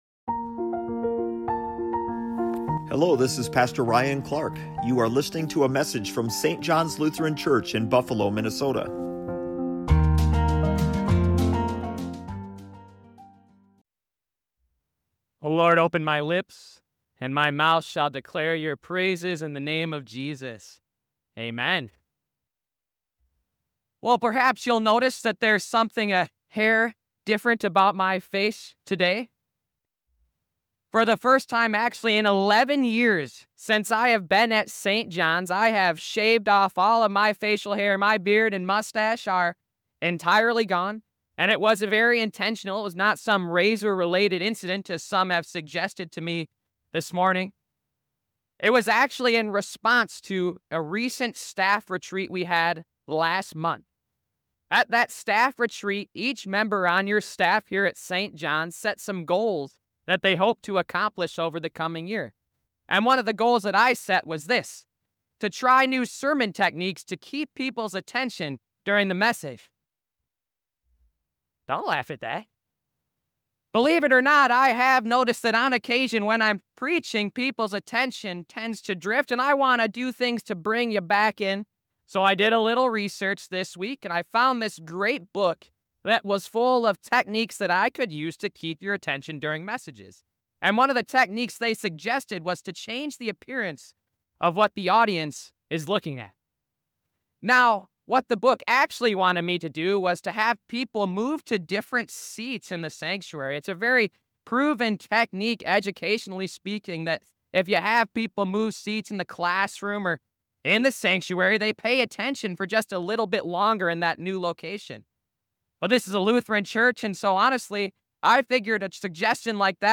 Sermon 4 in the series "Made for CommUNITY" - Love One Another